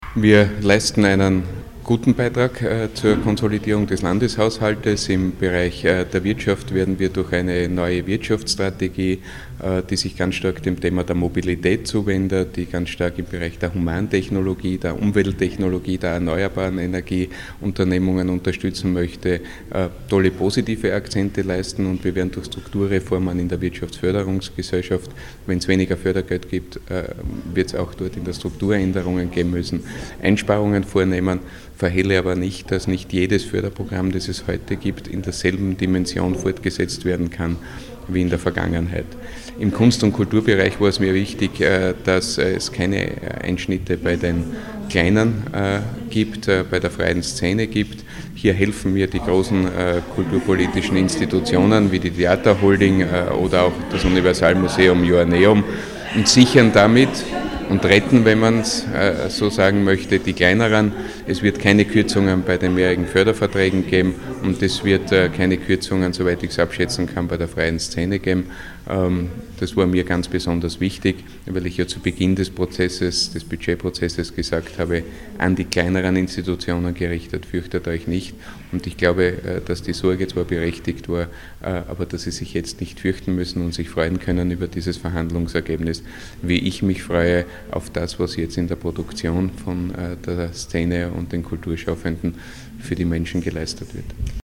O-Töne Landesrat Christian Buchmann: